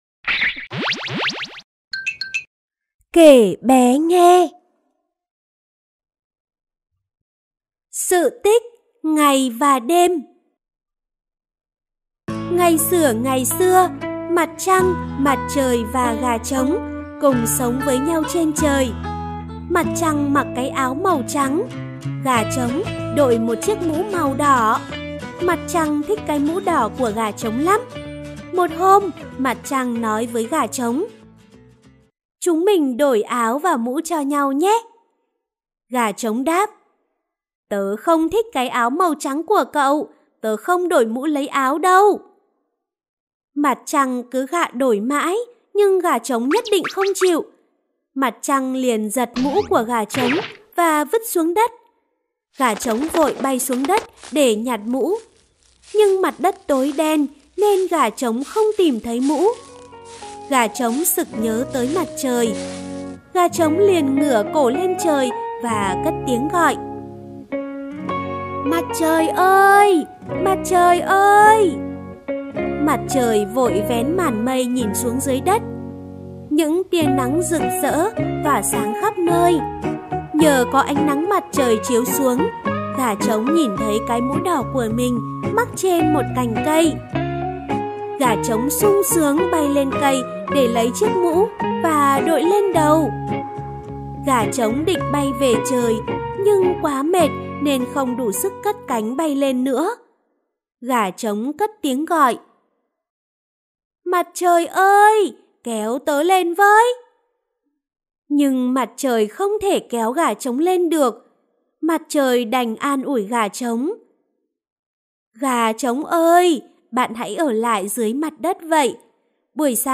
Sách nói | Sự Tích Ngày Và Đêm